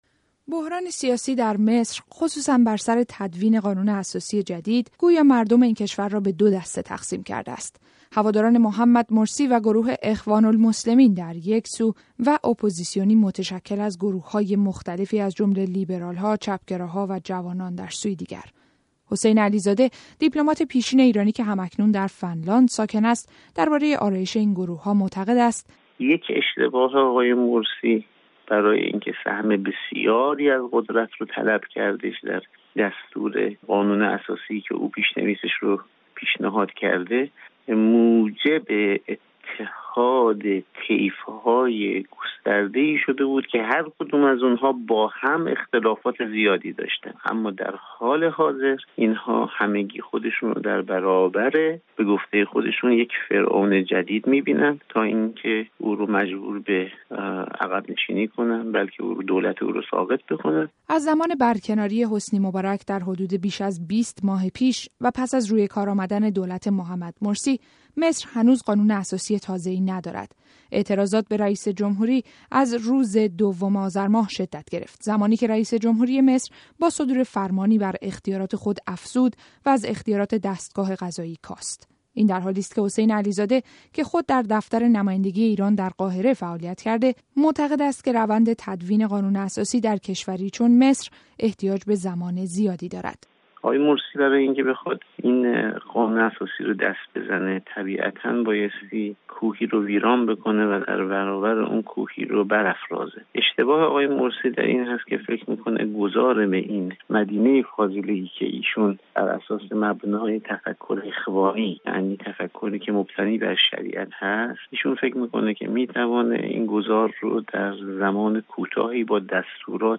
گزارشی تحلیلی از دور تازه اعتراضات در مصر